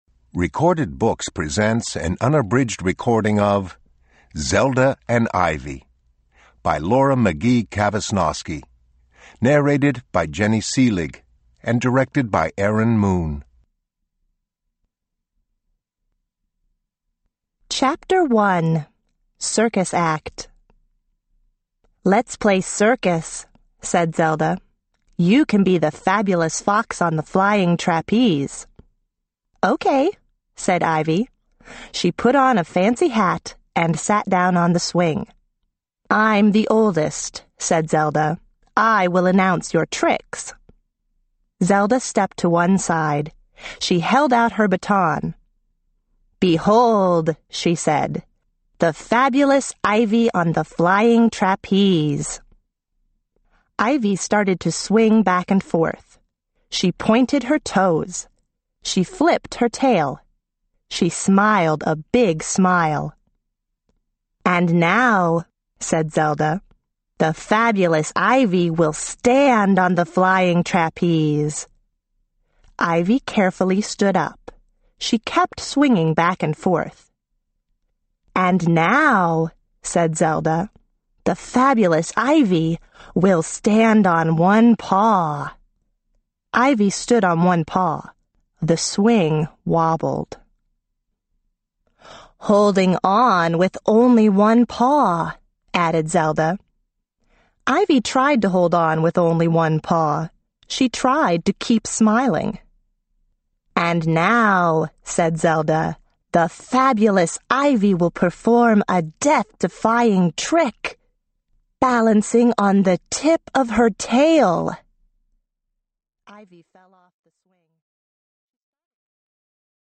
Unabridged
OverDrive MP3 Audiobook